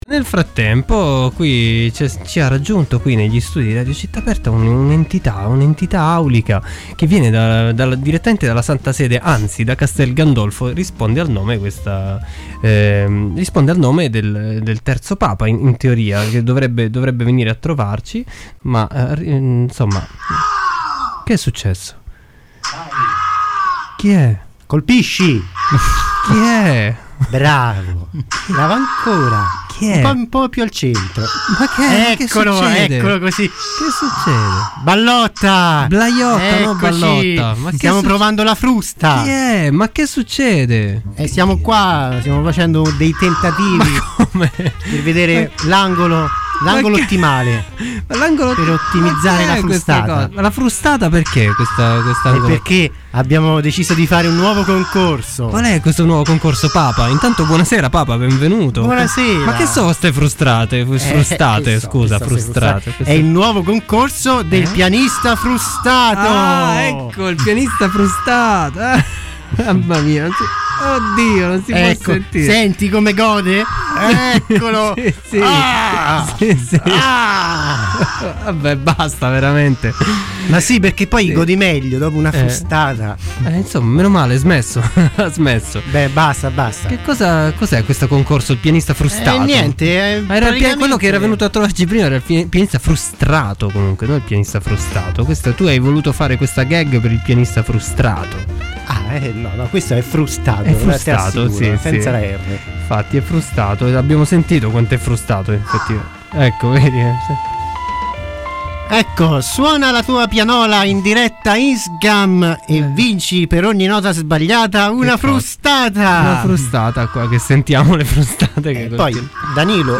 Sua Santità, il III Papa, irrompe negli studi di Radio Città Aperta, interrotto bruscamente da Maleficent!